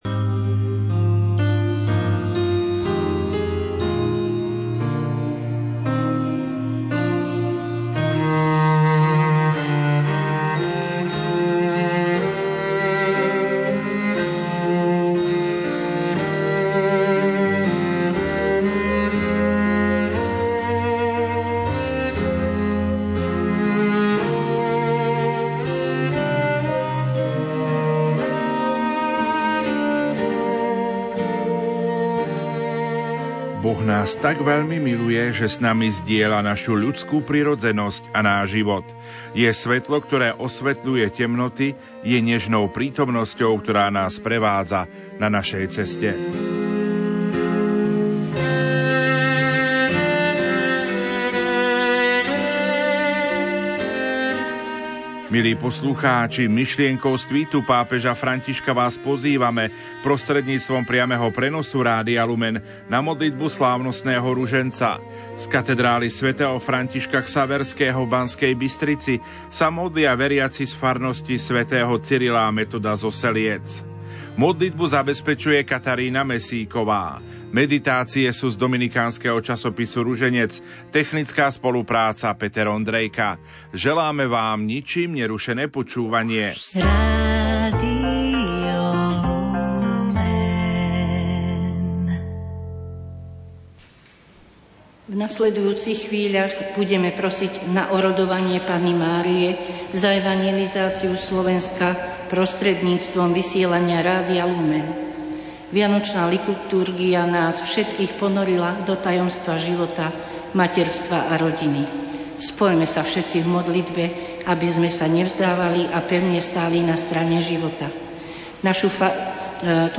modlitba slávnostného ruženca z Katedrály sv. Františka Xaverského v B.Bystrici modlia sa veriaci zo Seliec